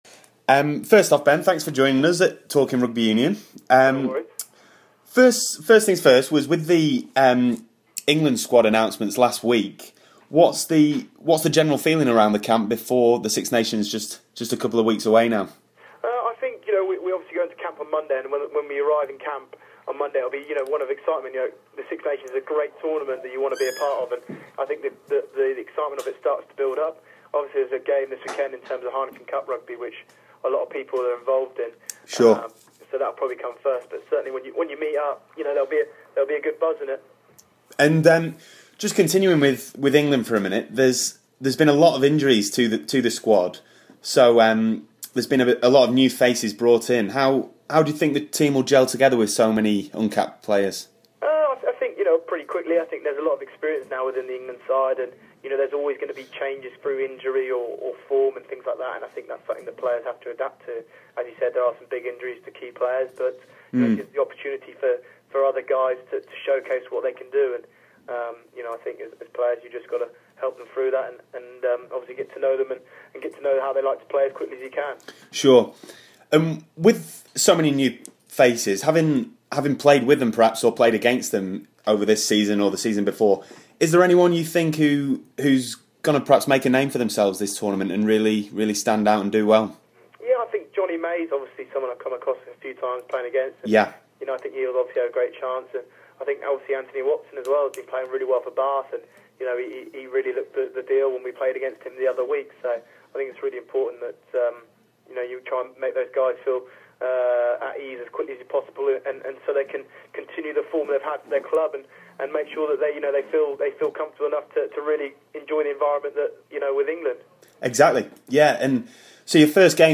TRU Exclusive Interview with Ben Youngs (PART 1)